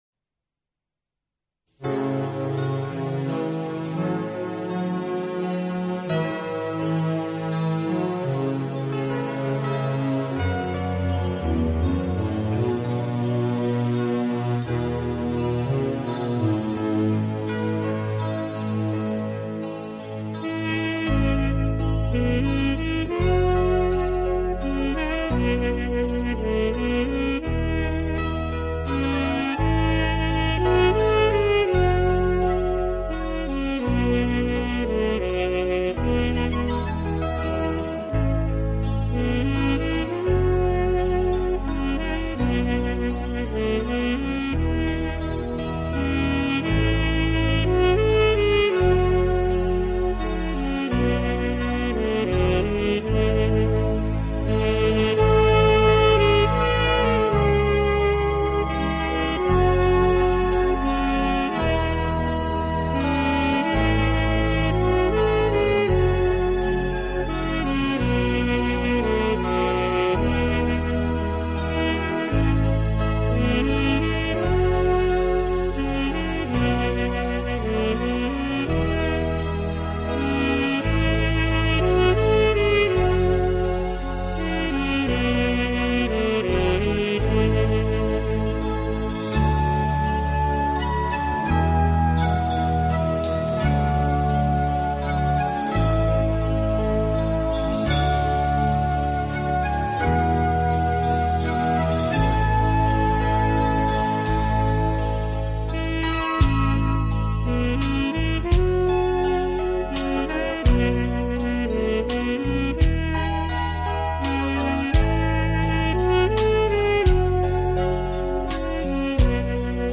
及其它类型音乐中，表现出杰出的抒情，浪漫风格普遍受到人们的喜爱。